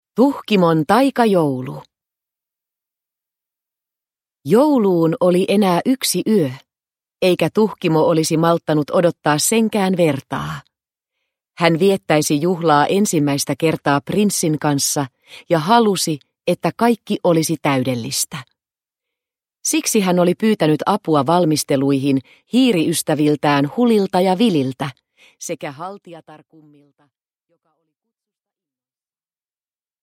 Tuhkimon taikajoulu – Ljudbok – Laddas ner